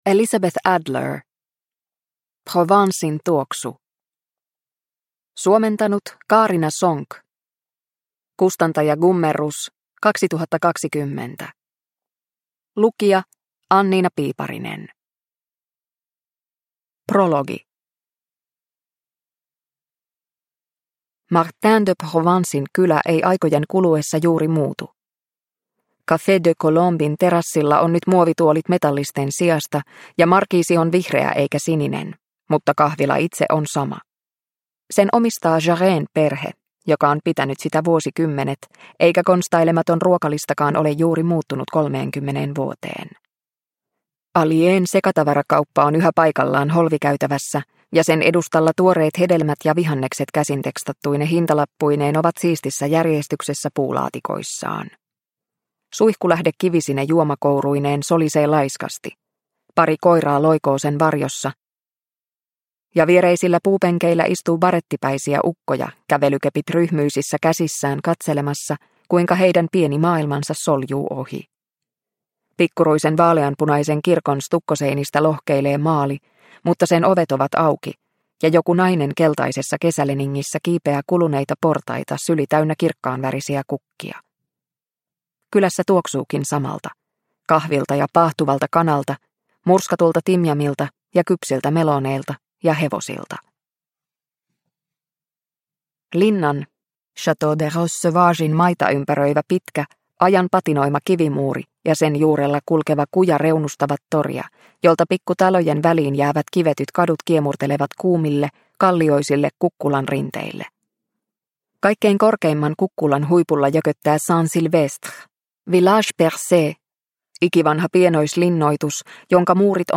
Provencen tuoksu – Ljudbok – Laddas ner